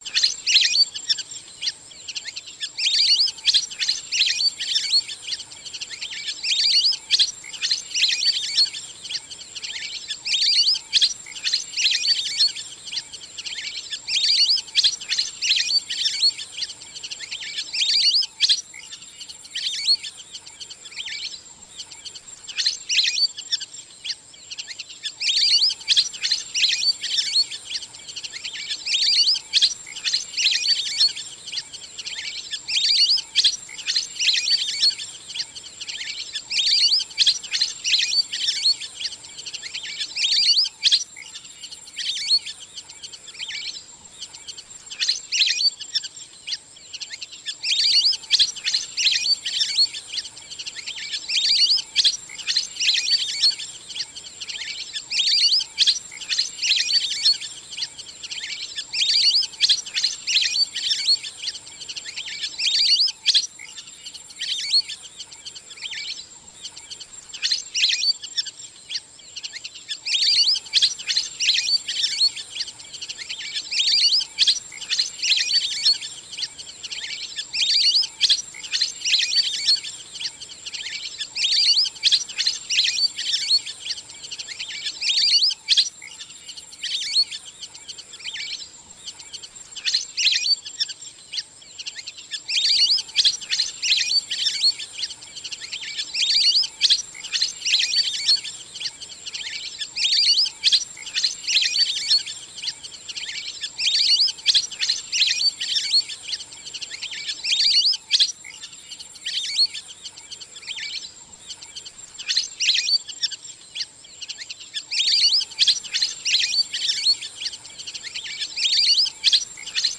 Tiếng Le Le kêu
Download tiếng Le Le kêu mp3, không có tạp âm. Con le le, hay còn gọi là vịt trời, sống ngoài tự nhiên và thường bay đi kiếm ăn vào chiều tối và mờ sáng.
Âm thanh tiếng Le Le kêu là một trong những âm thanh đặc trưng nhất của vùng sông nước, đầm lầy và các khu bảo tồn thiên nhiên. Tiếng kêu của loài chim này thường mang nhịp điệu nhanh, cao vút và có độ vang lớn trên mặt nước, tạo nên một bầu không khí hoang sơ, yên bình nhưng cũng rất sống động.